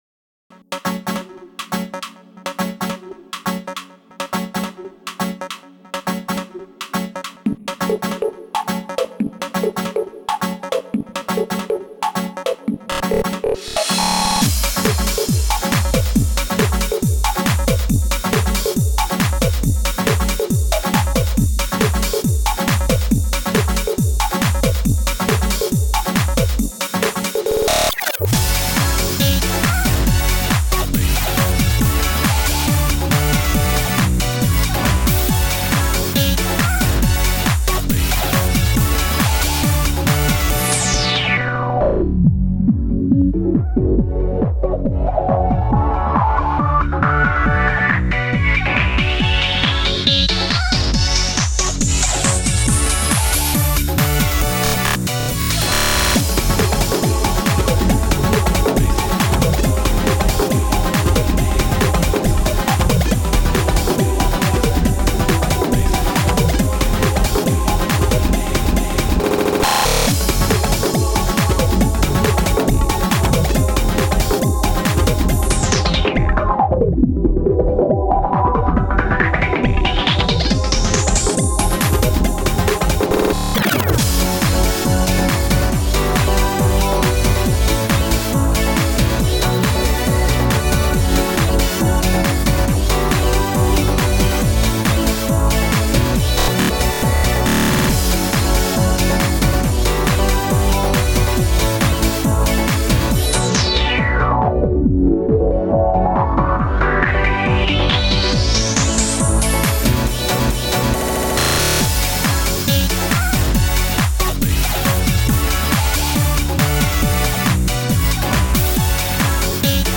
Tech House
曲の雰囲気としてはAct.1とAct.3にハウスの要素がプラスされたような感じです。
中盤以降は歌が入っていたりピアノのソロを入れたりとやりたいことはやってます。